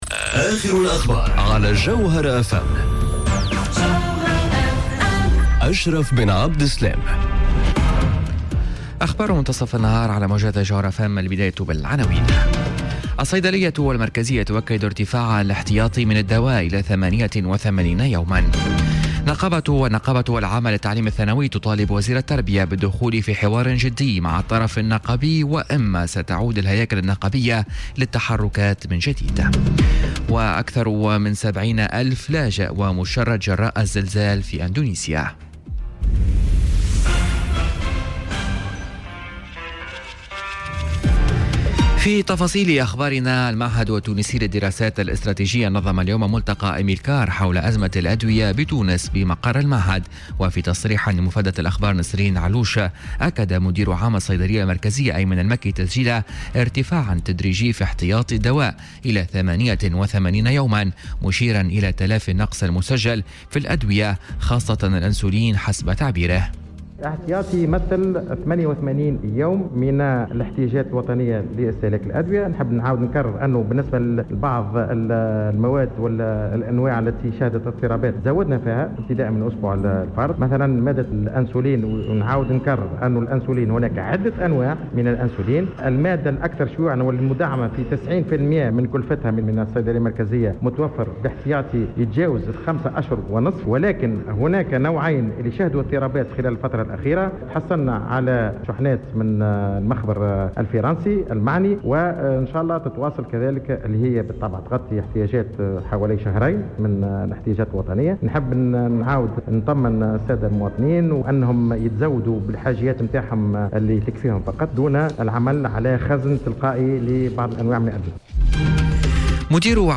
نشرة أخبار منتصف النهار ليوم الإربعاء 8 أوت 2018